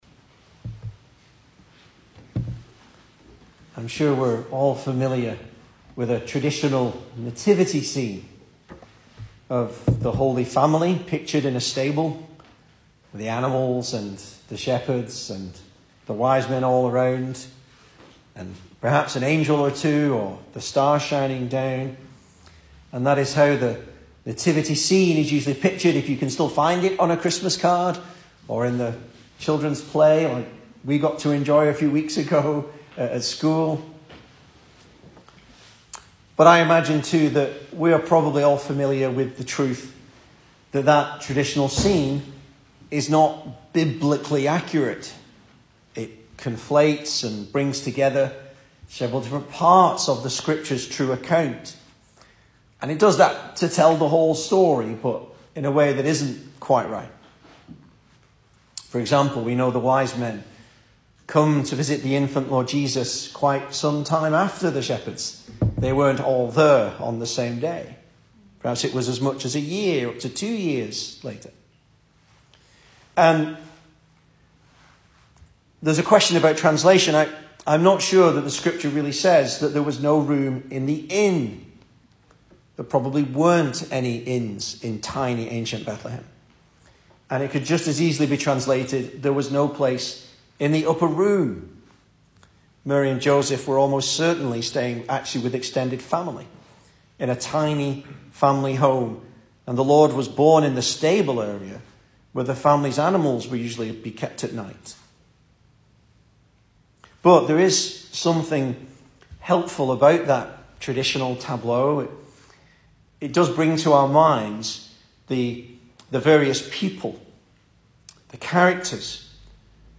Service Type: Sunday Evening
Christmas Sermons